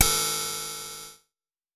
ChipTune Cymbal 02.wav